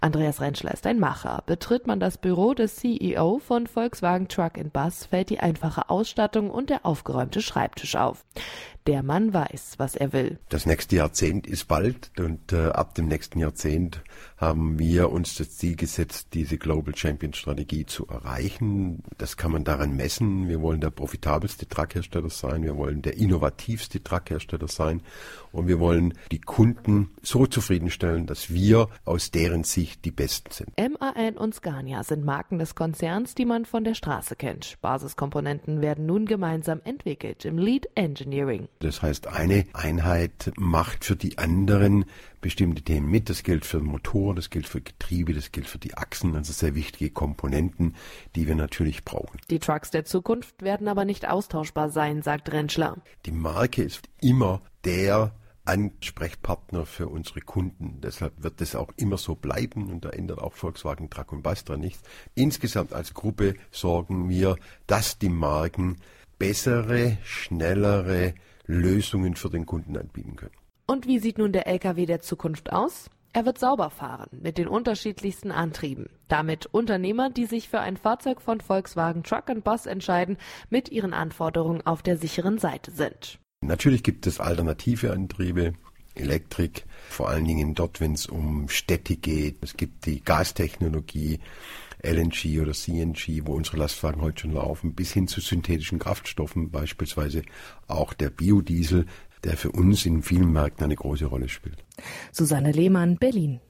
Allgemein, Auto, O-Töne / Radiobeiträge, , , , , ,